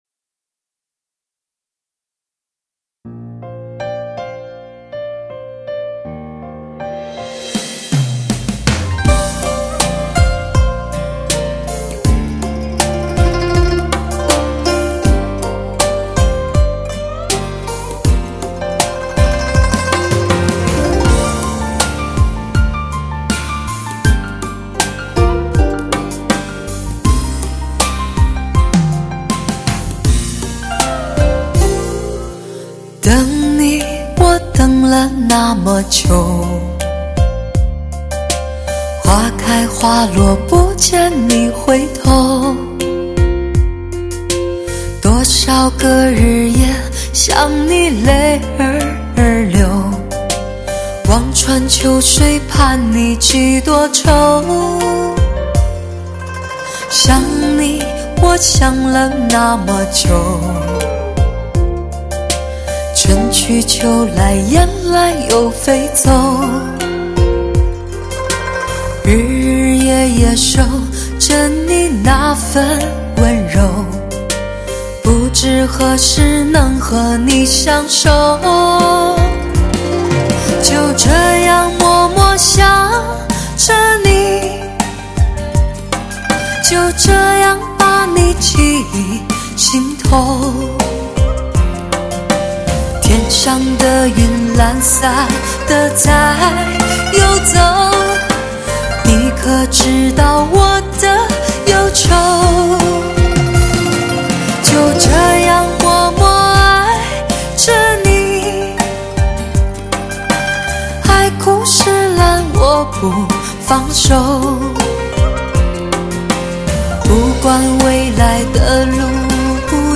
聆听最温情的声音，触动无限的情感，倾听无尽的倾诉.......